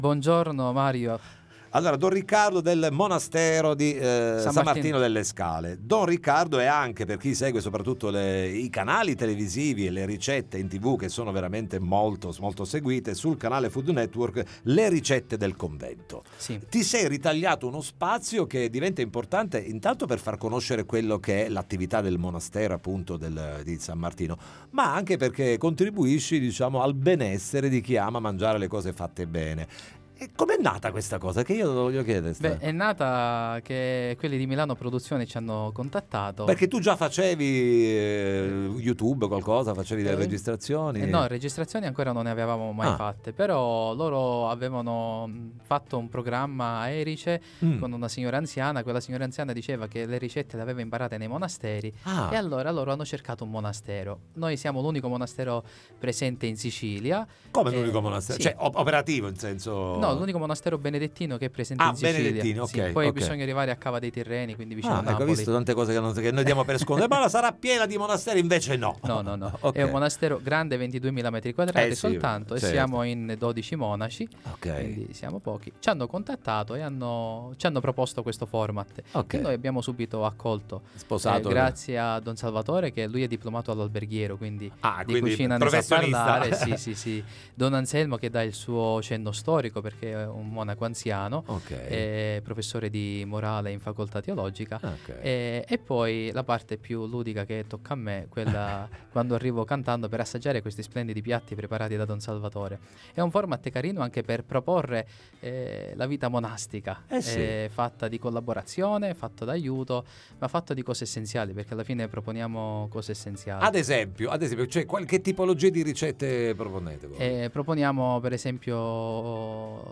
ne parliamo con lui nei nostri studimagaz